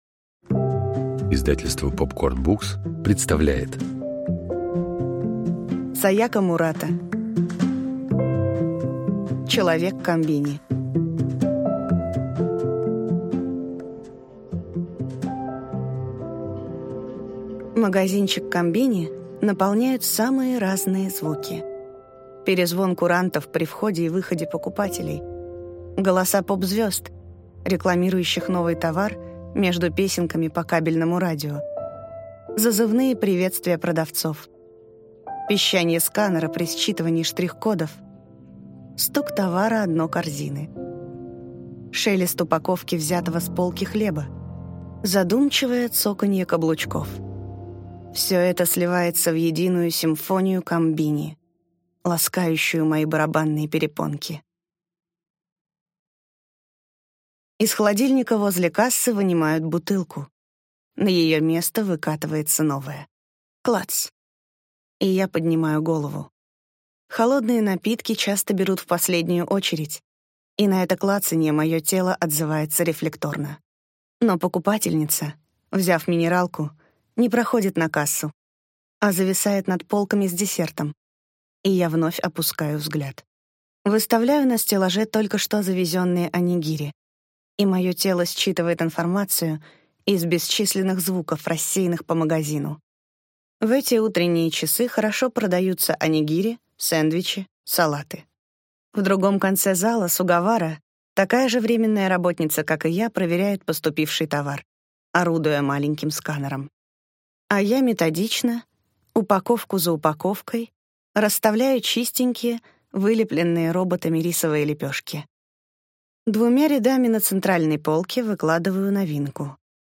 Аудиокнига Человек-комбини | Библиотека аудиокниг